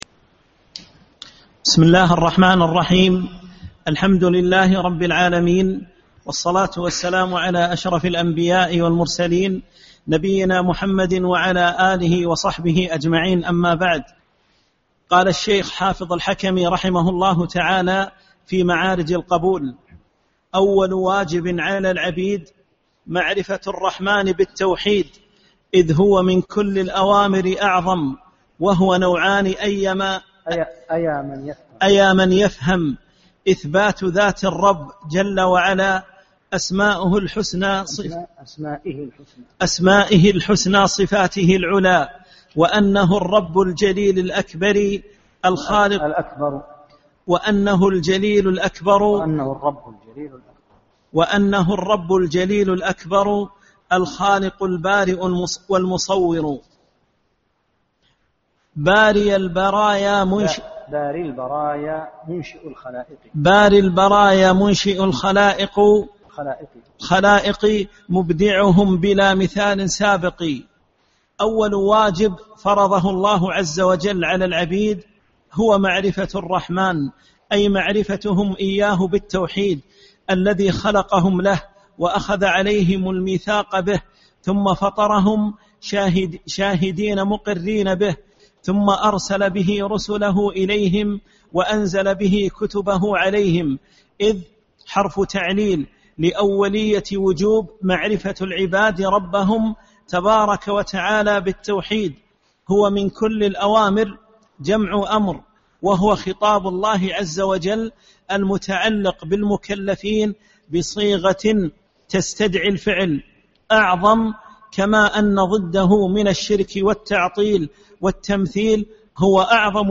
7- الدرس السابع